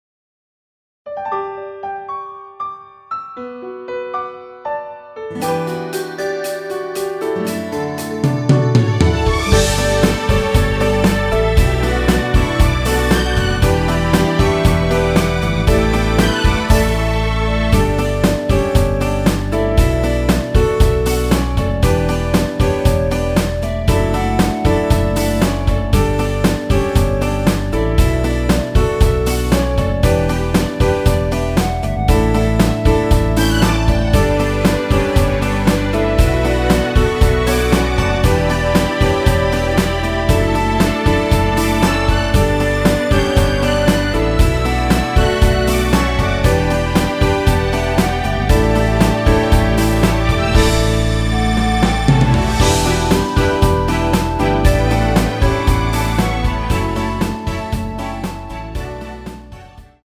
원키에서(+5)올린 멜로디 포함된 MR입니다.(미리듣기 참조)
앞부분30초, 뒷부분30초씩 편집해서 올려 드리고 있습니다.
중간에 음이 끈어지고 다시 나오는 이유는